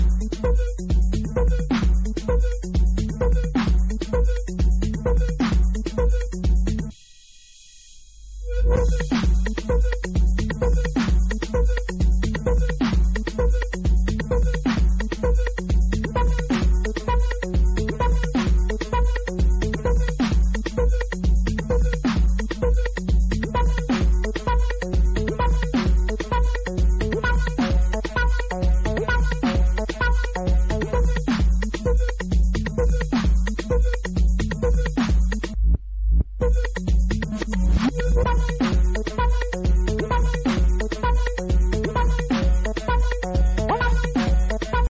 old school House classics